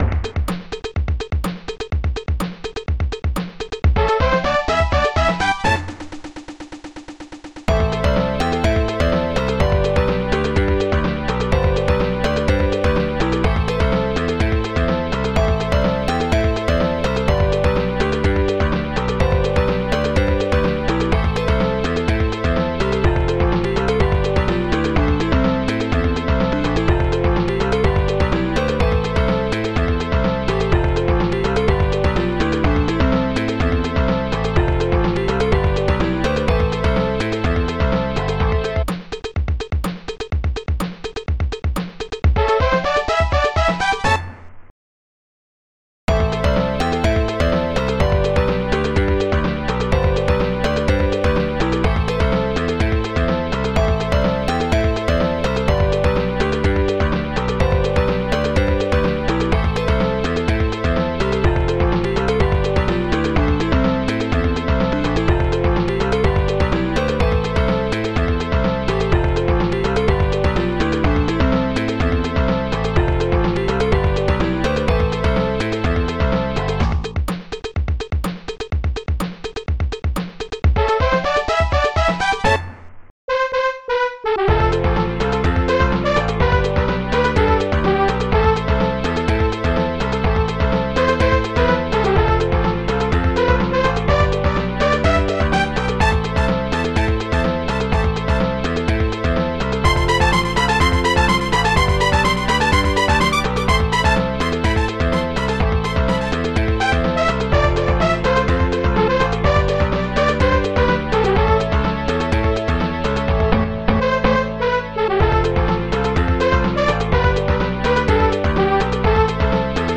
st-03:acsharpdrum st-05:basscool st-05:synthpro-dur st-05:synthpro-moll st-05:glockenspiel2 st-07:d1 st-08:bccowbell st-01:licks st-02:glockenspiel